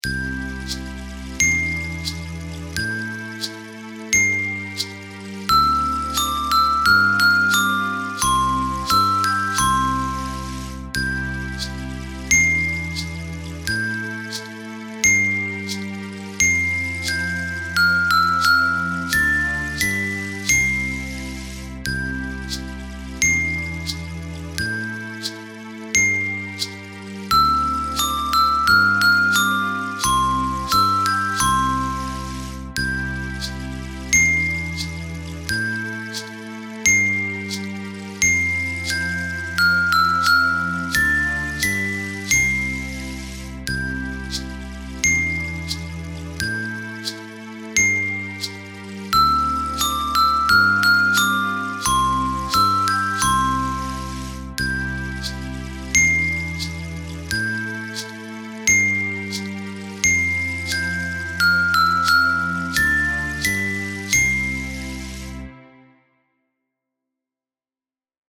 Chime Bars Ensemble